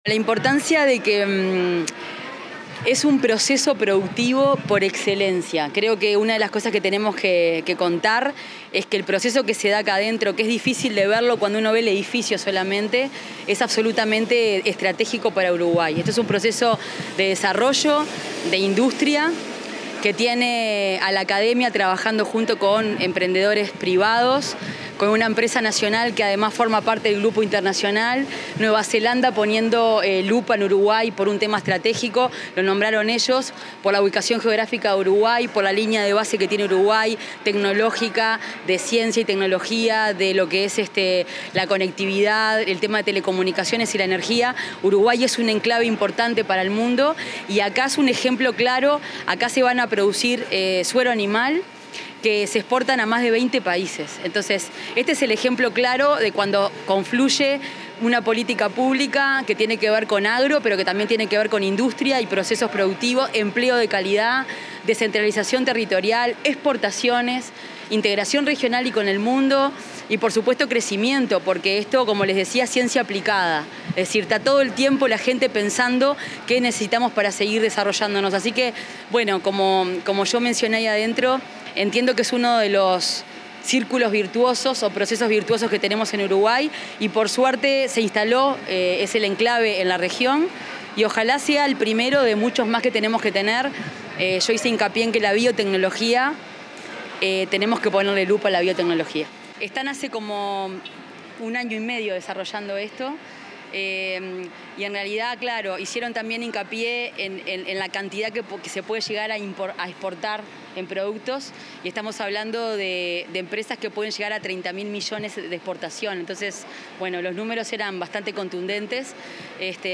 Declaraciones de la ministra de Industria, Fernanda Cardona
La ministra de Industria, Fernanda Cardona, dialogó con la prensa, luego de participar en la ceremonia de inauguración de una planta de MonteSera,